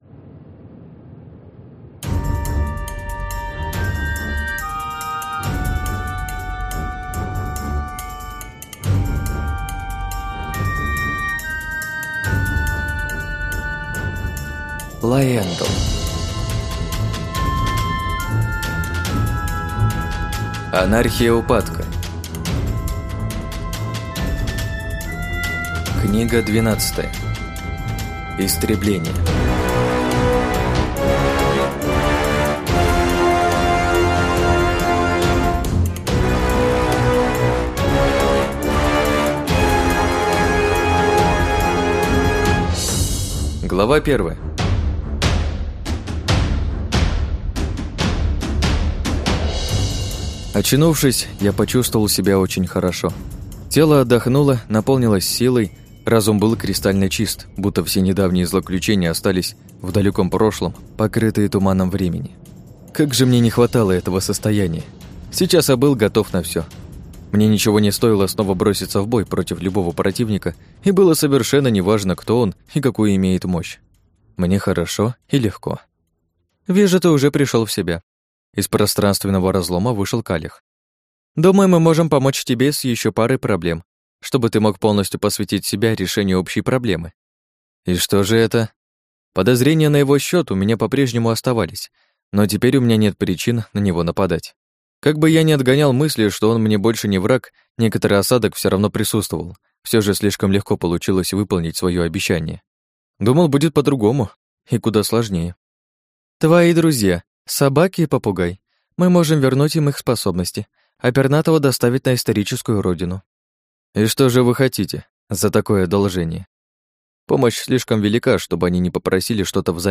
Аудиокнига Истребление | Библиотека аудиокниг